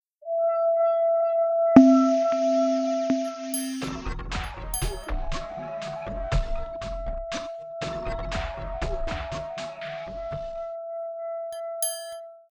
The first track is abrasive and shorter, featuring sounds of a glass rim and triangle.
Both tracks include space-age, engine like sounds, representing Eversley’s work in aerospace engineering, and LA in the sixties.